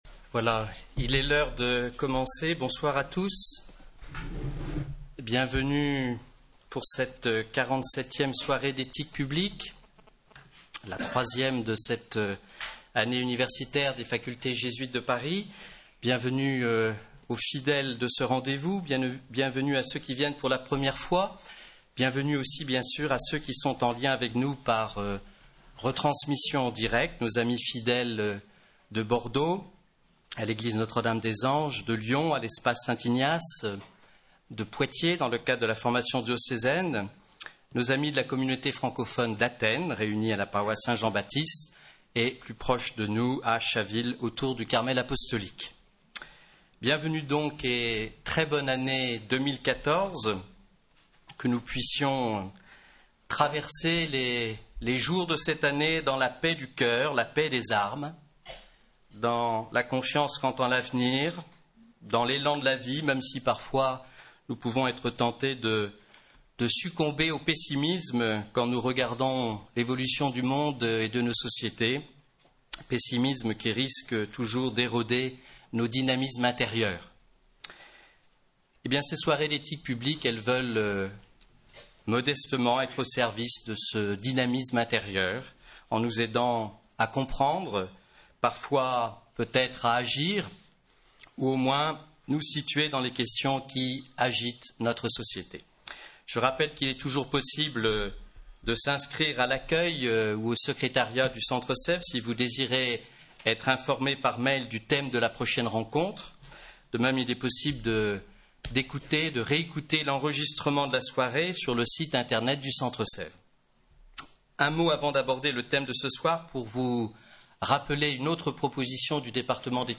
Soirée animée